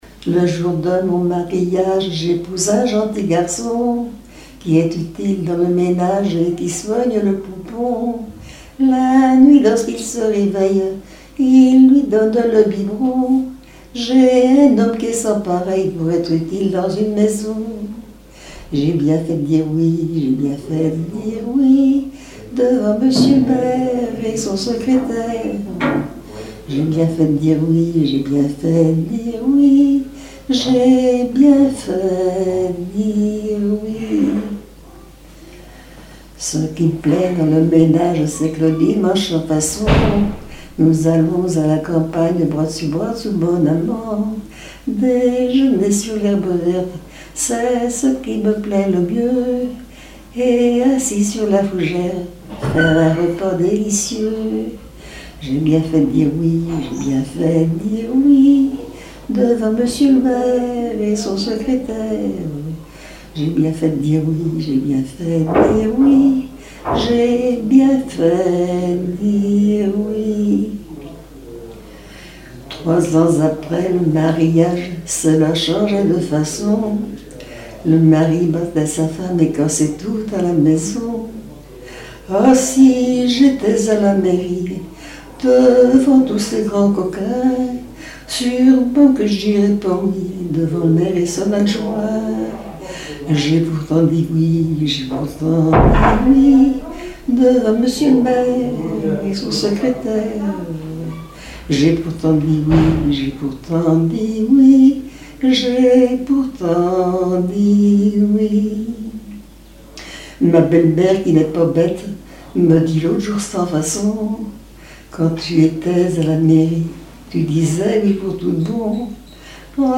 Genre strophique
Témoignages sur le mariages et des chansons
Pièce musicale inédite